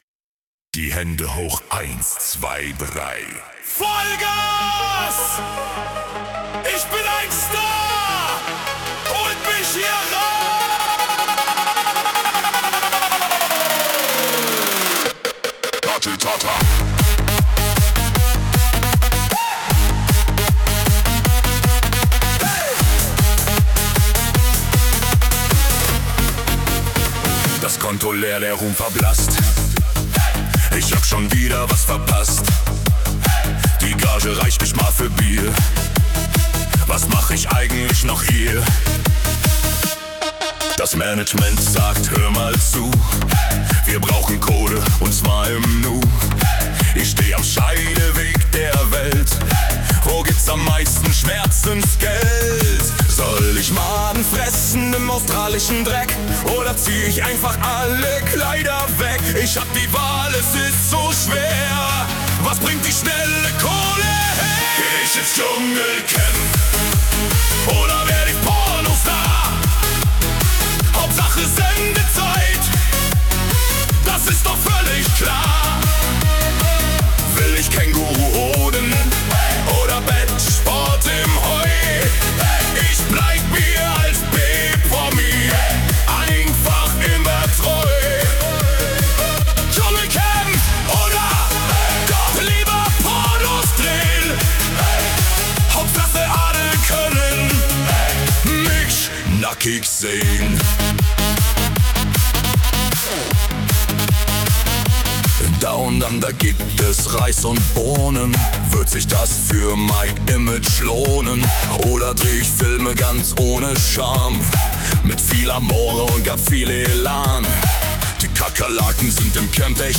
Apres Ski Hard Version